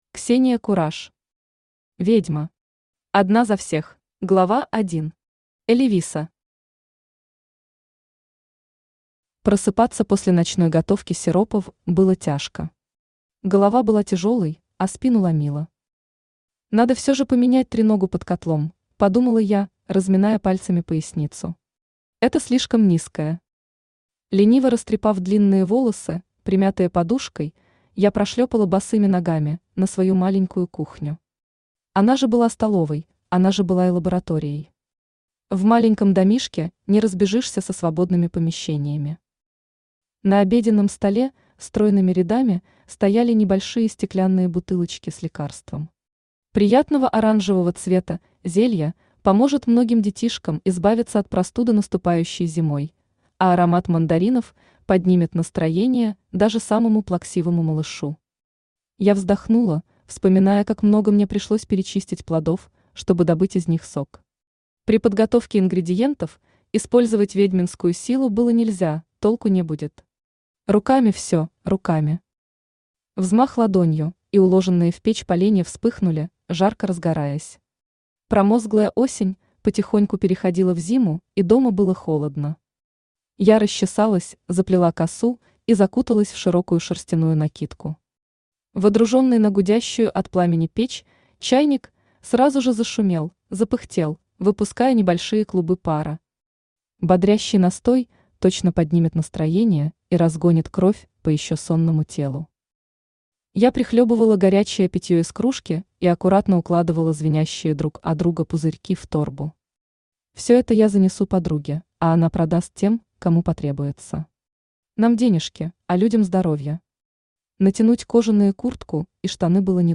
Аудиокнига Ведьма. Одна за всех | Библиотека аудиокниг
Одна за всех Автор Ксения Кураш Читает аудиокнигу Авточтец ЛитРес.